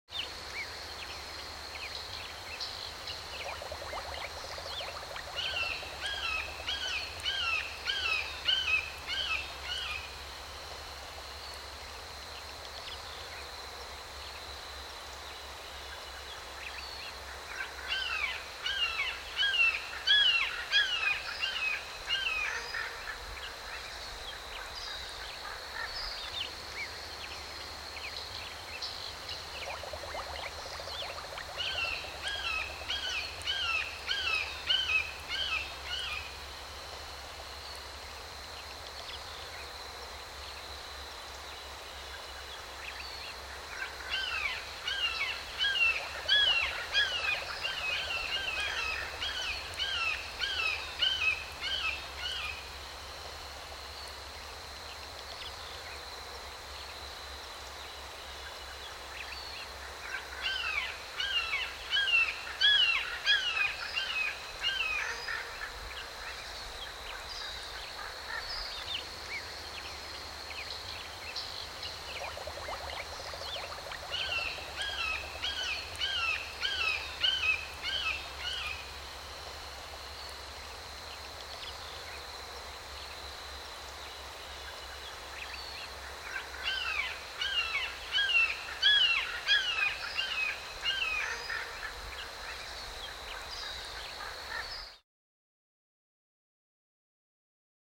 دانلود صدای پرنده 7 از ساعد نیوز با لینک مستقیم و کیفیت بالا
جلوه های صوتی
برچسب: دانلود آهنگ های افکت صوتی انسان و موجودات زنده دانلود آلبوم صدای پرندگان و حشرات از افکت صوتی انسان و موجودات زنده